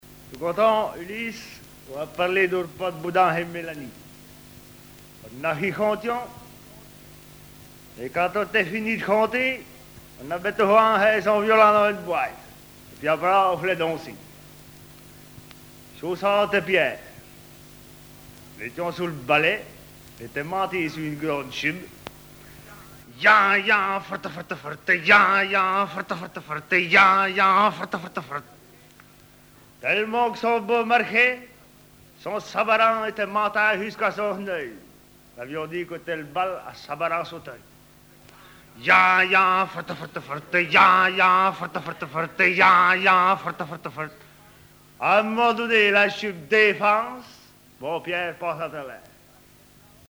Genre récit
histoires en patois poitevin
Catégorie Récit